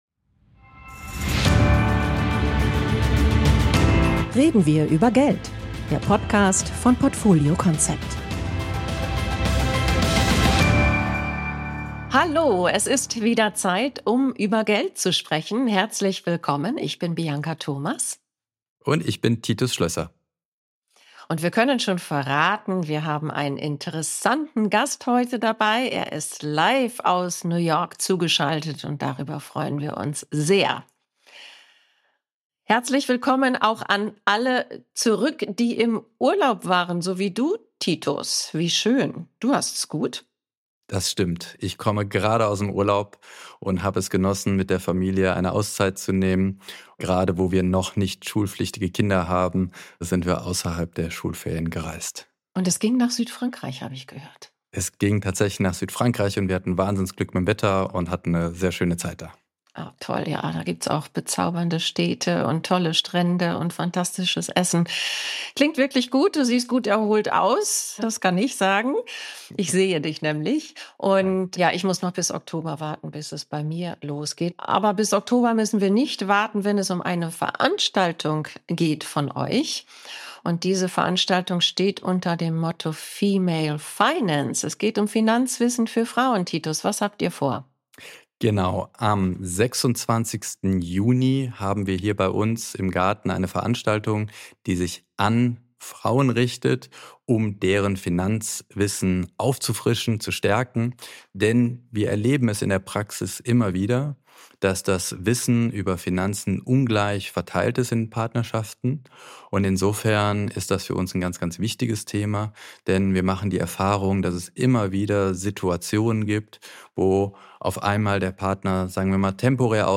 Gemeinsam analysieren sie die aktuelle Stimmung an den US-Finanzmärkten, werfen einen kritischen Blick auf Trumps Wirtschaftspolitik und diskutieren, wie sich steigende Preise und neue Zölle auf den Alltag der Amerikaner und die globalen Börsen auswirken.